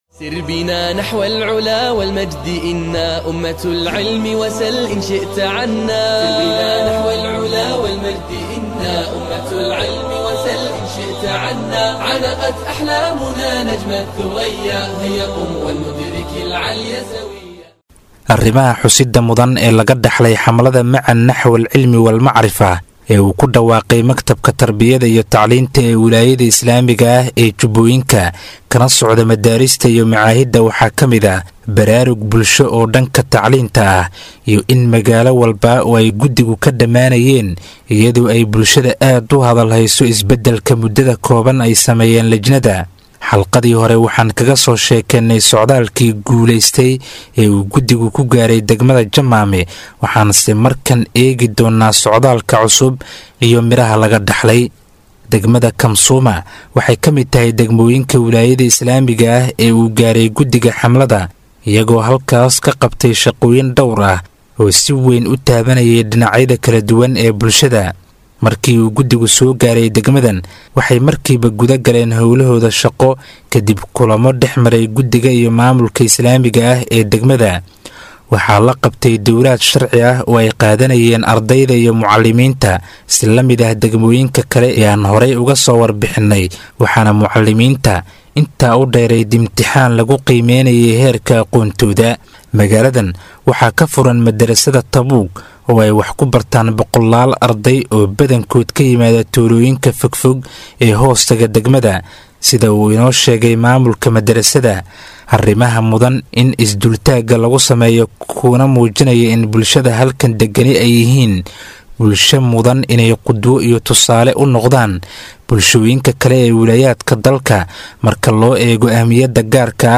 Hadaba halkan hoose waxan idiinku soo gudbin doonaa warbixintii idaacadda Andalus ay ka diyaarisay waxbarashada degmada Kamsuuma oo maqal ah iyo sawirada waxbarashada degmada.